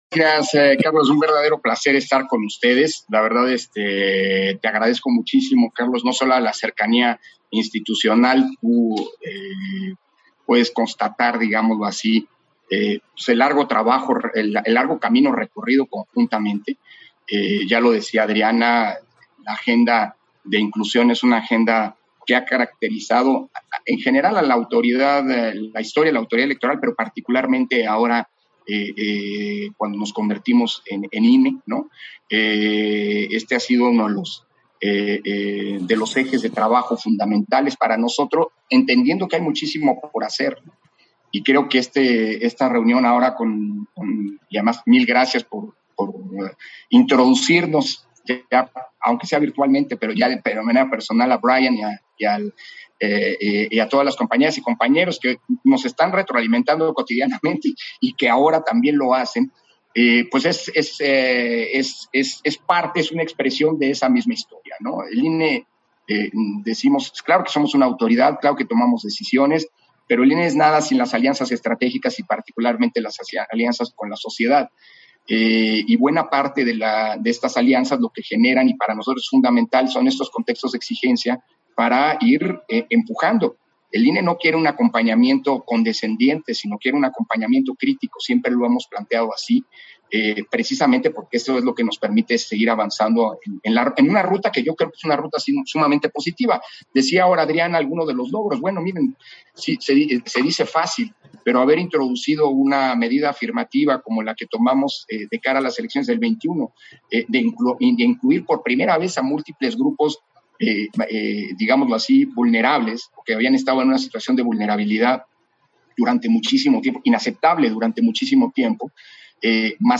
Intervenciones de Consejeras y Consejeros del INE